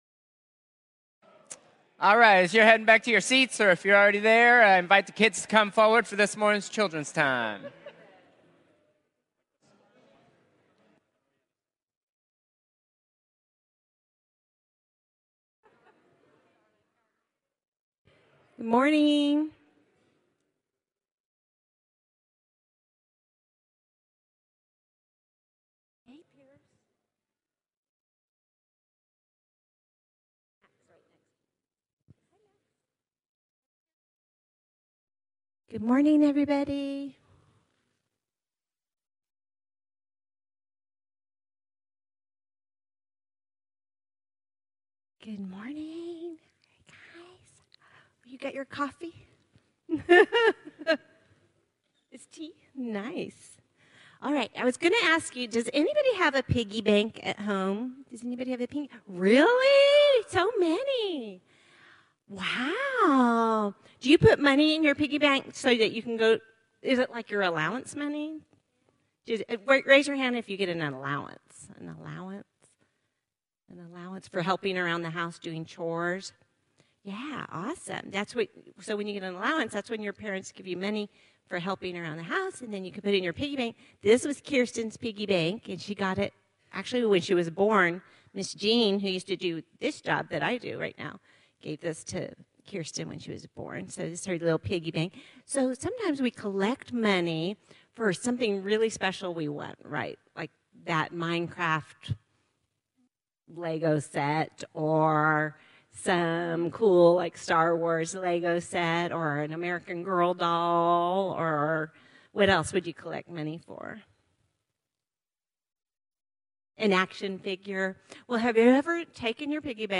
Children’s Time